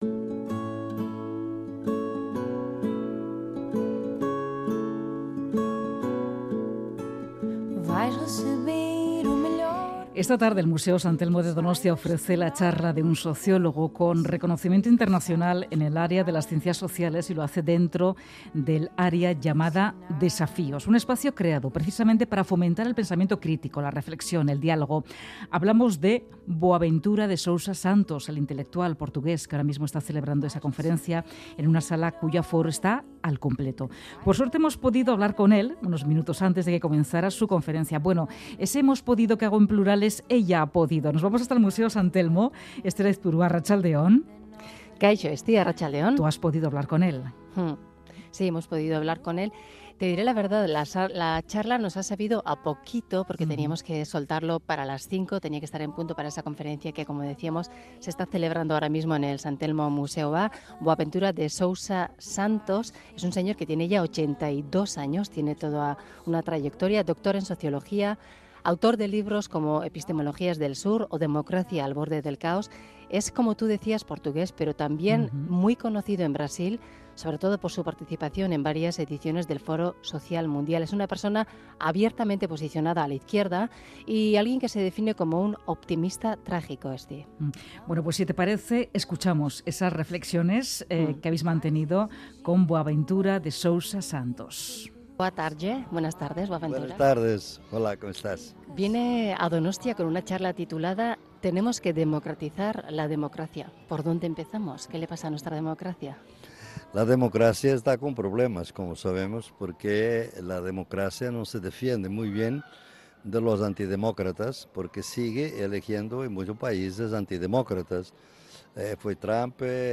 Charlamos con el reputado sociólogo Boaventura de Sousa Santos sobre el auge de la ultraderecha, las elecciones de Brasil, la democracia y lo que está sucediendo en el Mediterráneo.